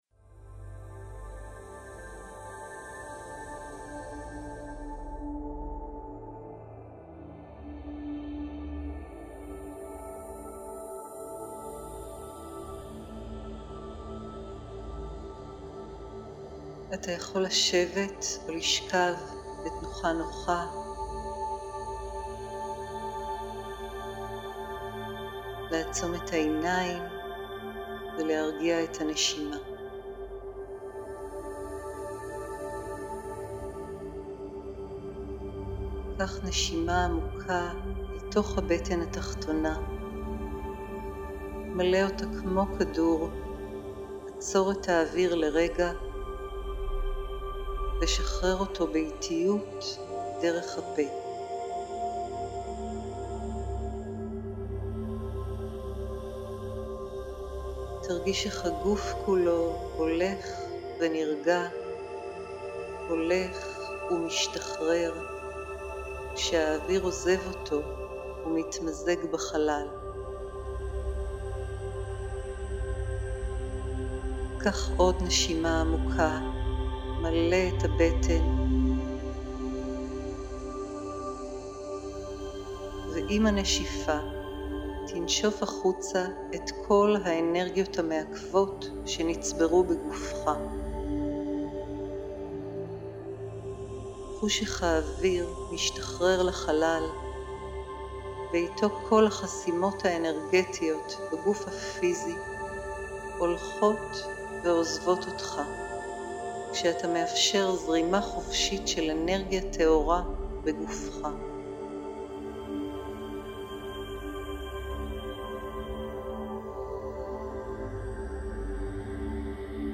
תרגיל דמיון מודרך שמטרתו לעורר את האנרגיה המינית עם תדר בינוראלי של פעימת כדור הארץ, התהודה של שומאן.
אנרגיית כדור הזהב – דמיון מודרך רוחני לניקוי חסמים גבולות ומגבלות:
מדיטציית כדור הזהב, מדיטציה רוחנית לעבודה עם האנרגיה המינית עם תדר "התהודה של שומאן"